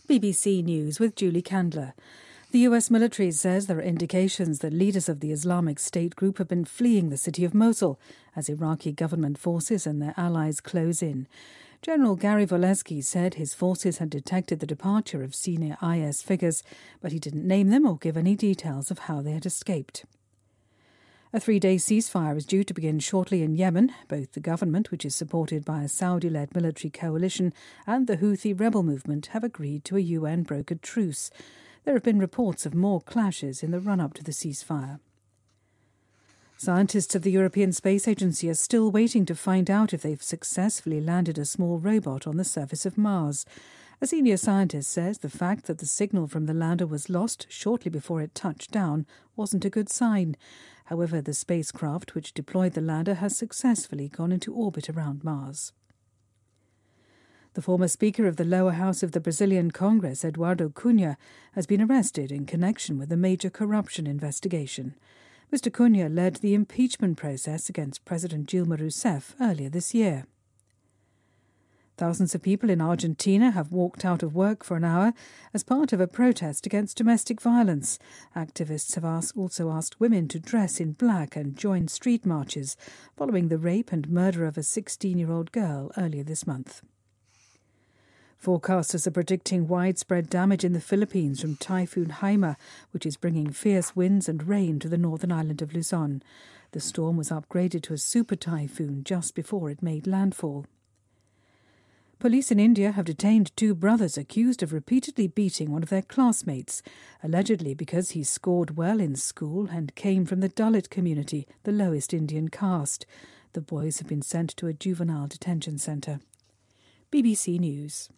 日期:2016-10-21来源:BBC新闻听力 编辑:给力英语BBC频道